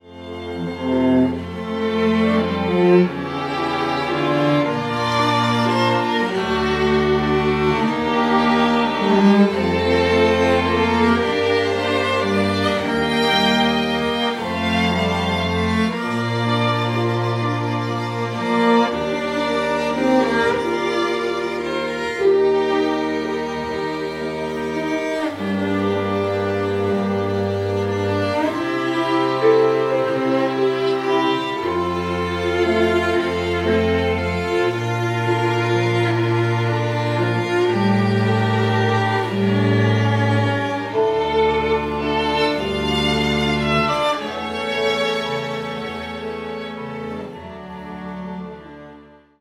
Ein Konzertabend in stimmungsvoller Atmosphäre
„Tanz der Schneeflocken“ für Streichquintett und Harfe.
Ein stimmungsvoller Musikabend endete mit dem Applaus der freundlichen Zuhörer, die gerne noch zahlreicher hätten sein dürfen.